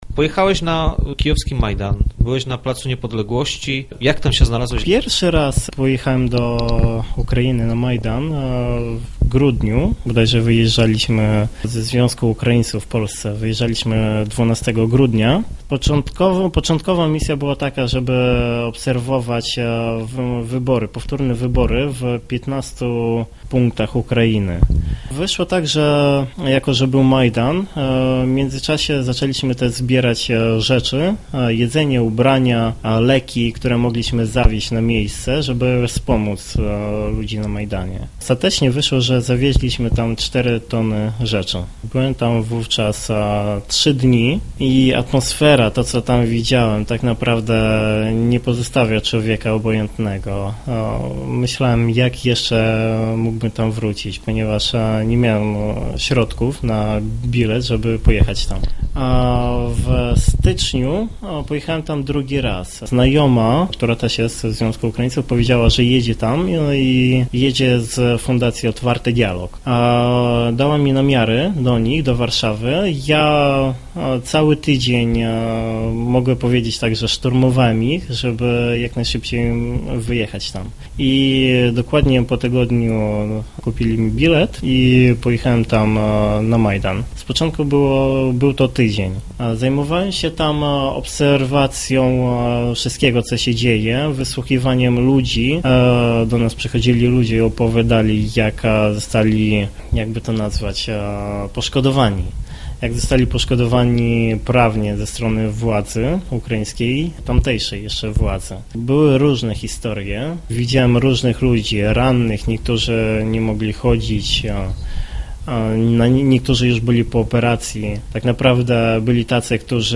Widział ludzkie dramaty i tragedie. Dzisiaj wierzy w wolną i niepodległą Ukrainę. Po przyjeździe do Lubina podzielił się z nami swoją historią.